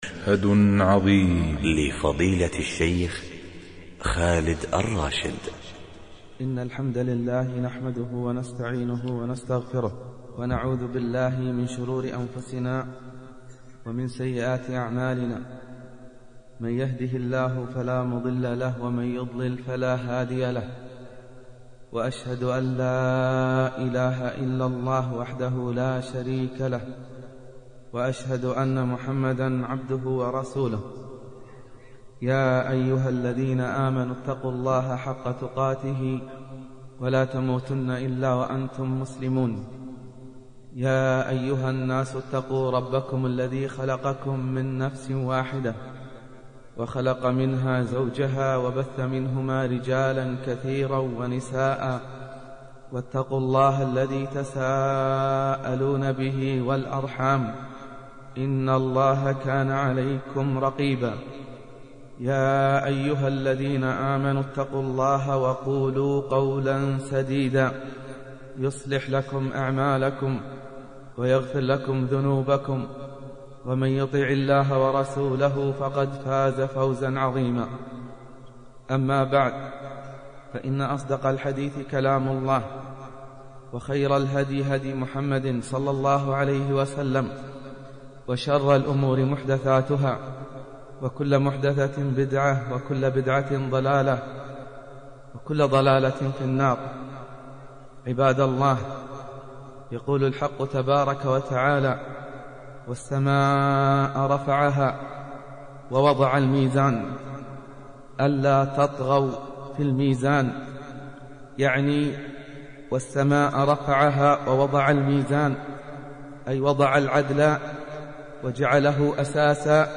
الملخص: المحاضرة تدور حول الميزان يوم القيامة كأحد أعظم مشاهد العدل الإلهي: الميزان حق له كفتان، توزن